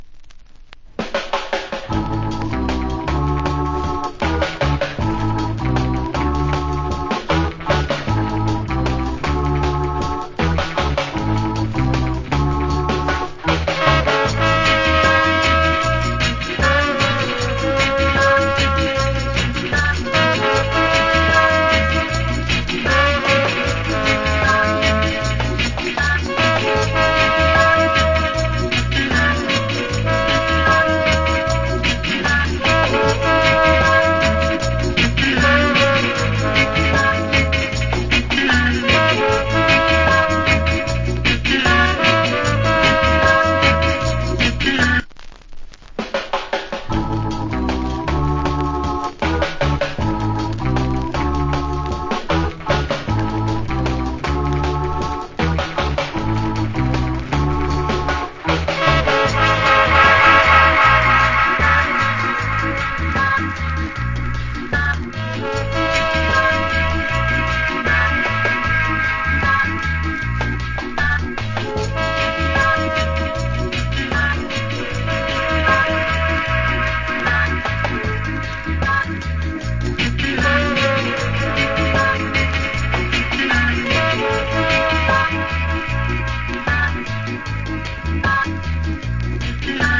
Wicked Reggae Inst.